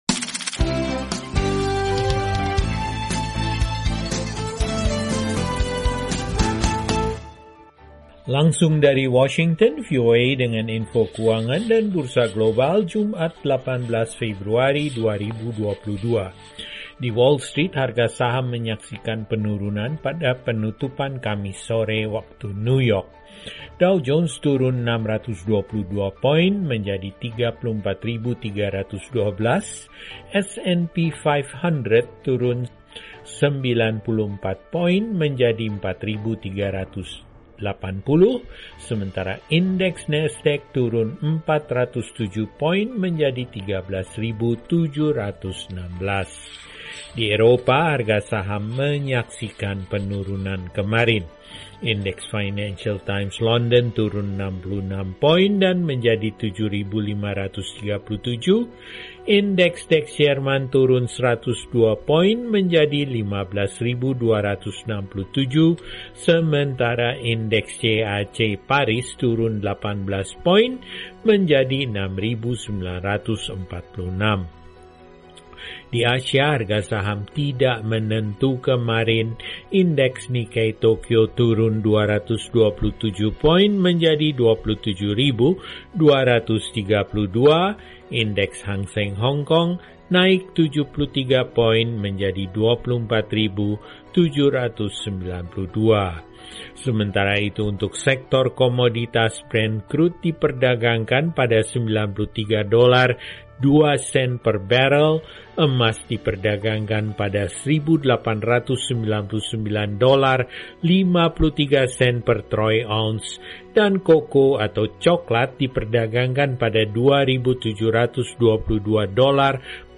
Berikut laporan dari bursa global dan pasar keuangan, serta sebuah laporan tentang inflasi di Turki.